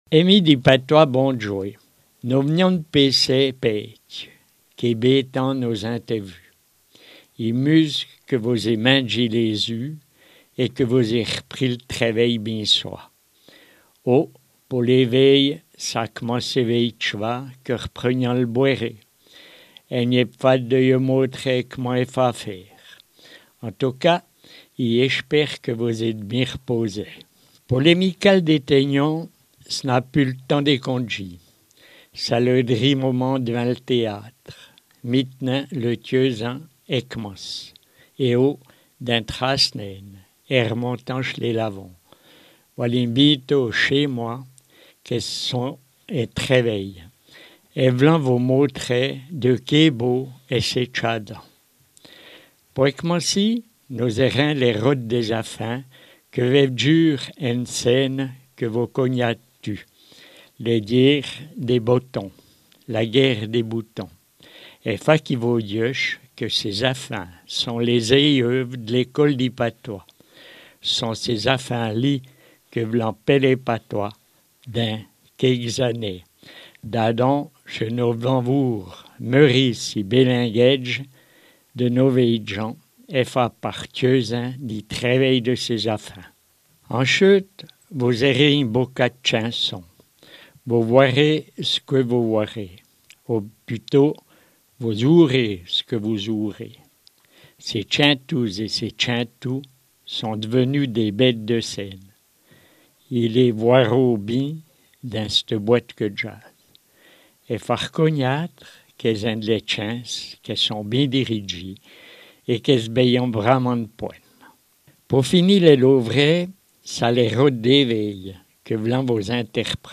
rubrique en patois, 28 avril 2019